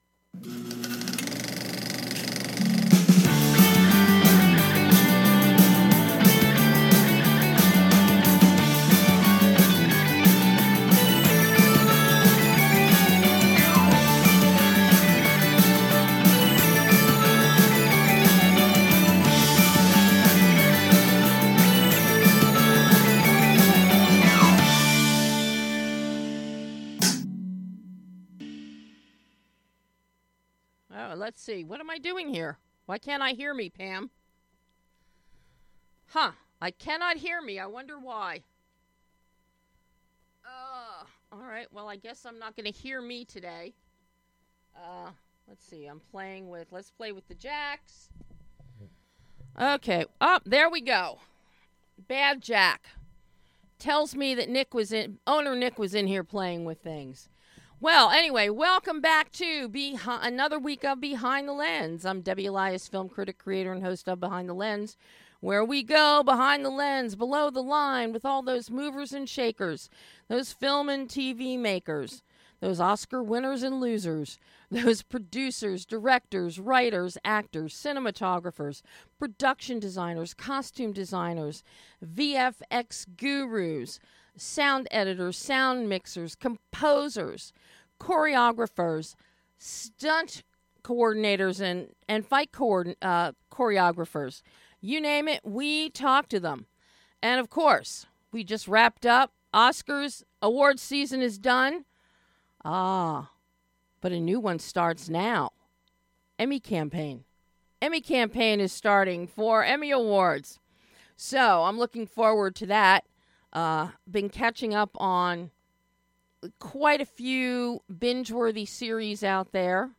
First up is my exclusive interview with director DITO MONTIEL talking the brilliantly conceived RIFF RAFF.